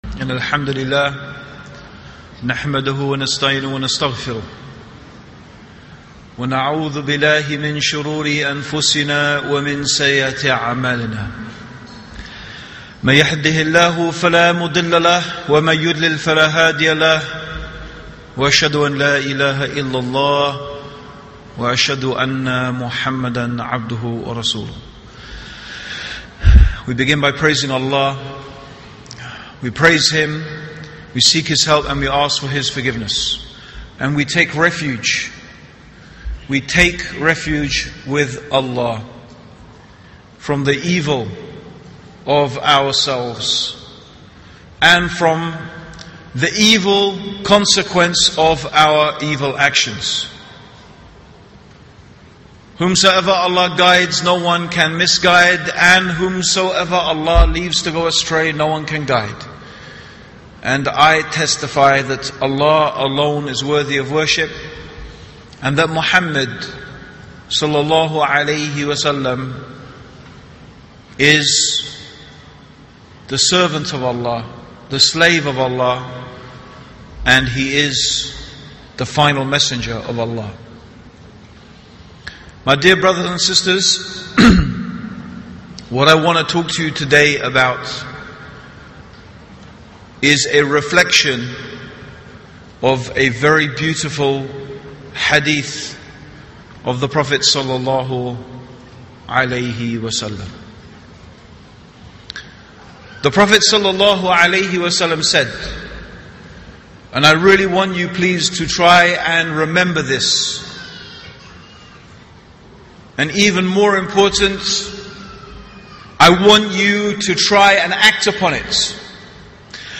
What truly holds the Muslim ummah together — and what tears it apart? In this powerful talk